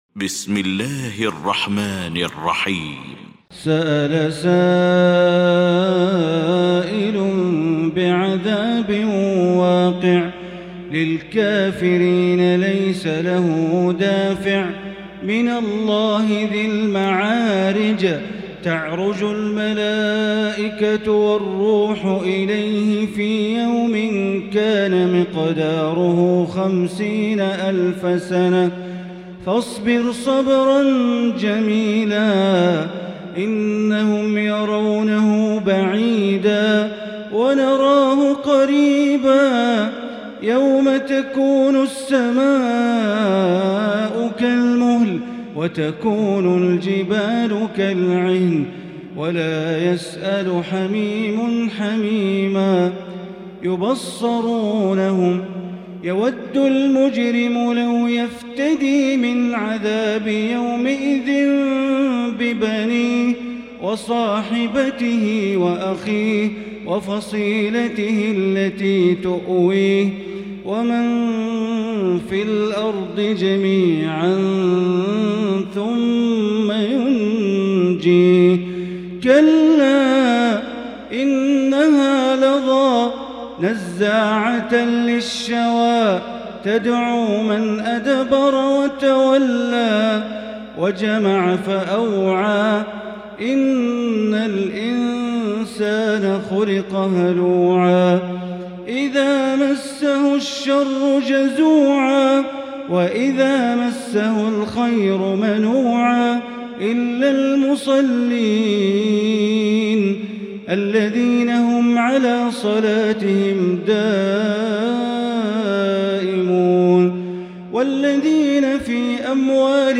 المكان: المسجد الحرام الشيخ: معالي الشيخ أ.د. بندر بليلة معالي الشيخ أ.د. بندر بليلة المعارج